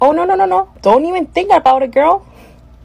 no no no girl dont even think about it Meme Sound Effect
no no no girl dont even think about it.mp3